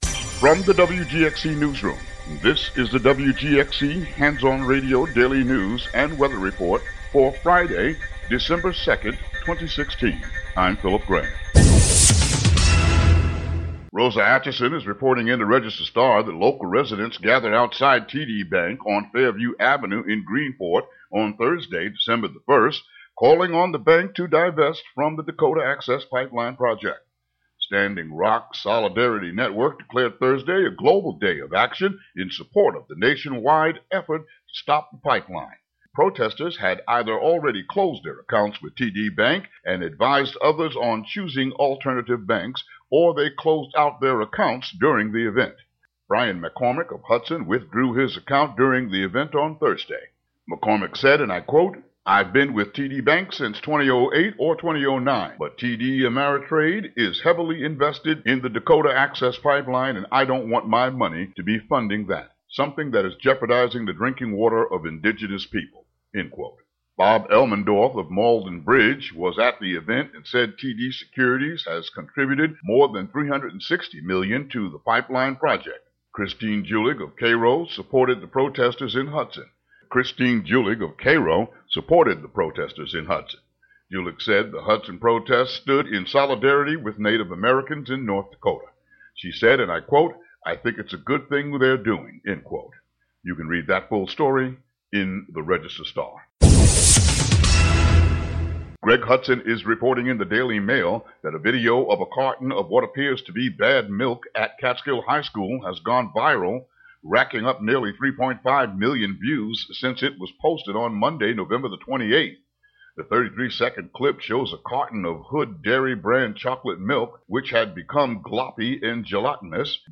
Daily local news for WGXC.